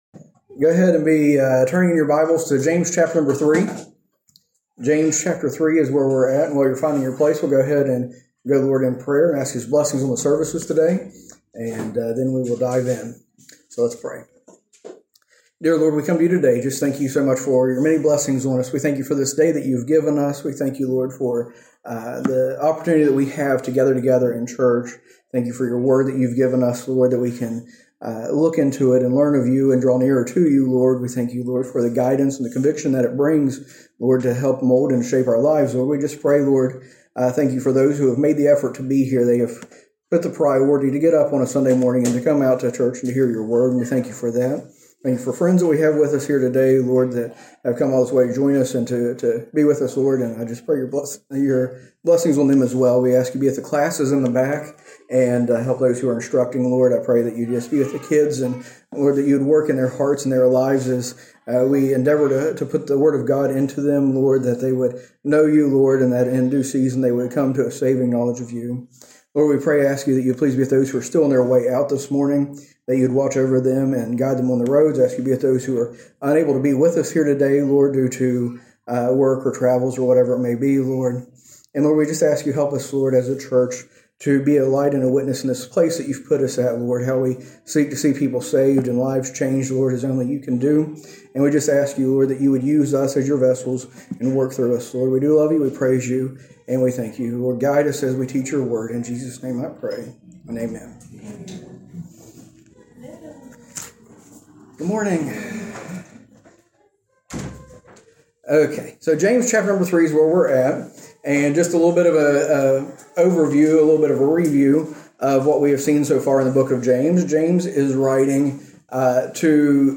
A message from the series "James: Faith in Action."